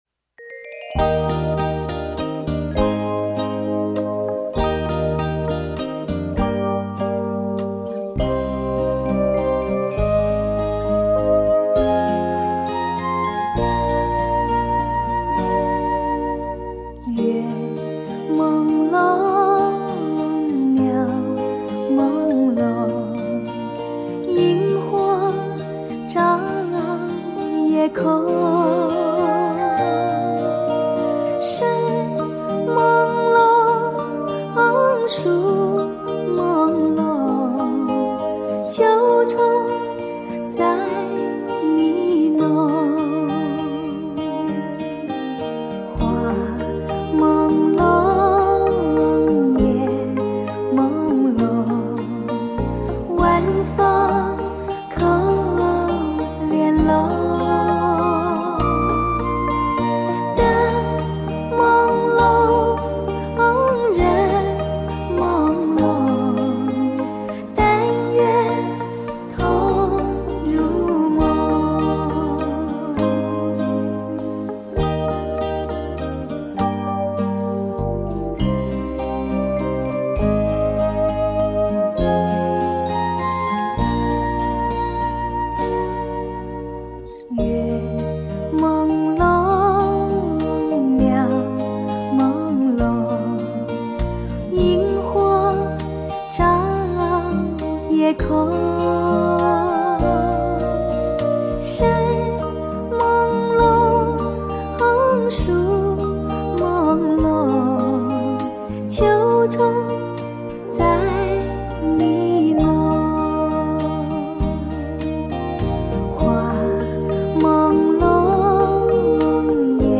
完美诠释淬选经典金曲所交织而来的顶级甜歌录音之作。